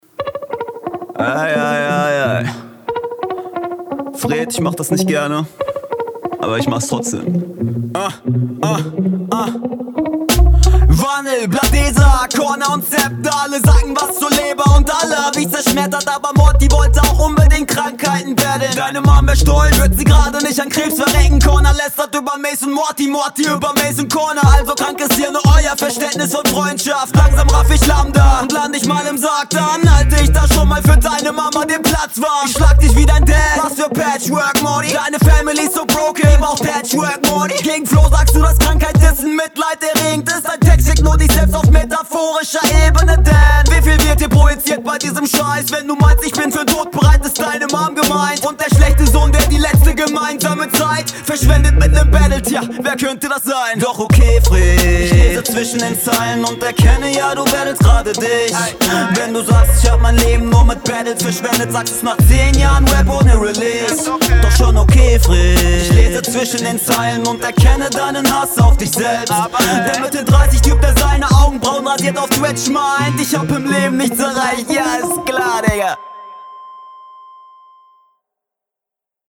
Beat anlaufen lassen und einsteigen eben.
Ich finde deinen Stimmeinsatz leider durchgehend etwas überzogen!